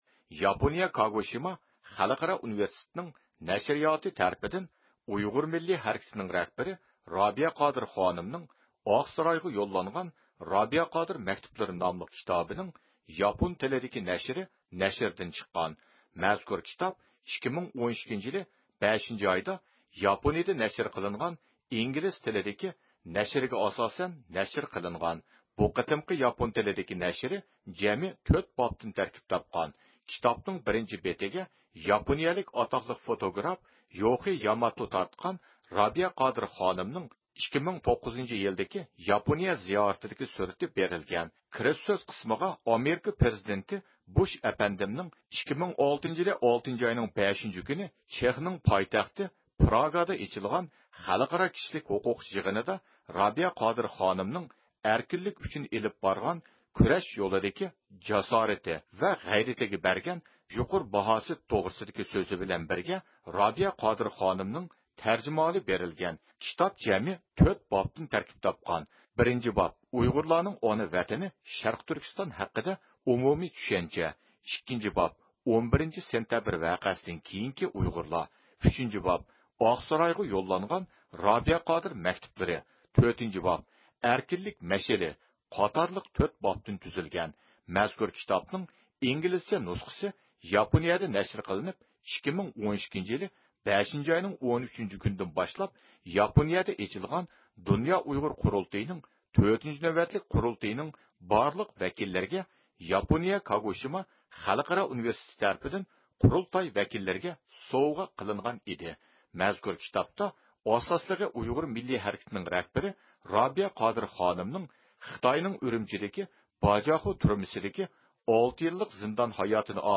بىز ئۇيغۇر مىللىي ھەرىكىتىنىڭ رەھبىرى رابىيە قادىر خانىمنىڭ ياپونىيەدە نەشر قىلىنغان ياپون تىلىدىكى مەزكۇر كىتابى توغرىسىدا ياپونىيەدىكى بىر قىسىم مۇتەخەسسىسلەر بىلەن سۆھبەت ئېلىپ باردۇق.